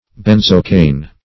benzocaine - definition of benzocaine - synonyms, pronunciation, spelling from Free Dictionary